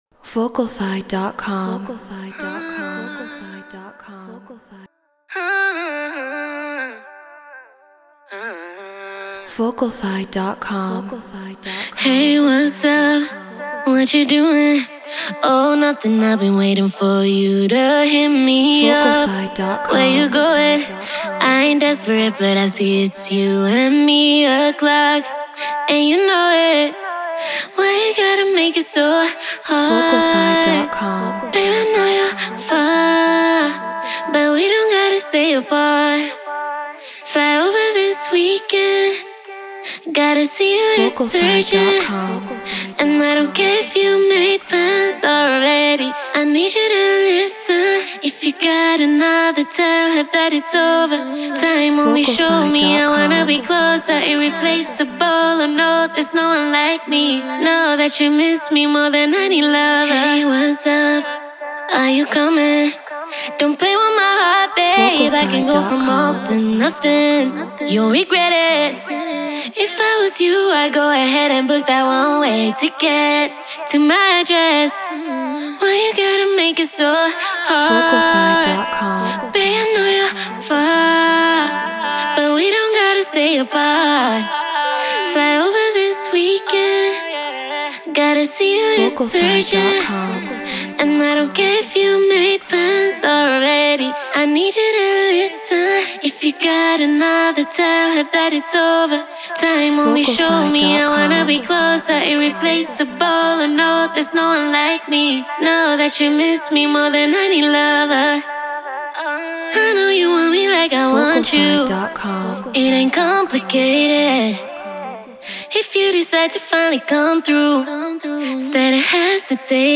RnB 160 BPM Dmin